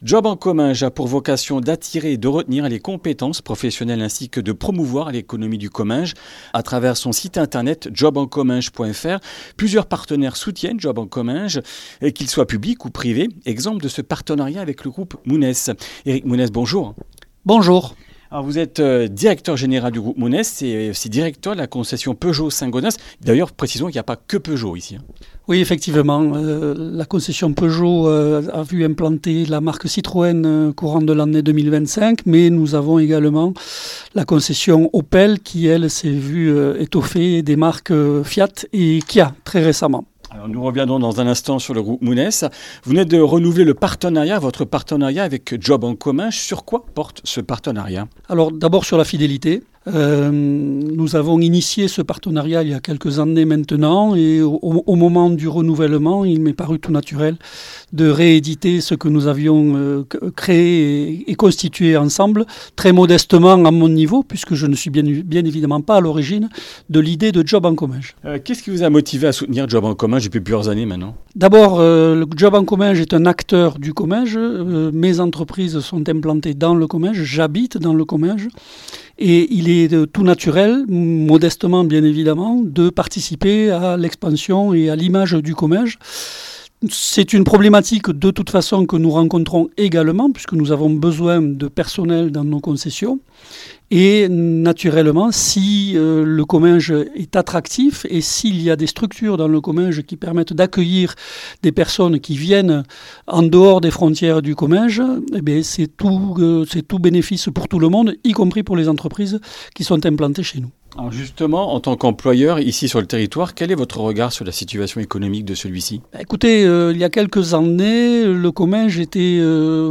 Comminges Interviews du 23 déc.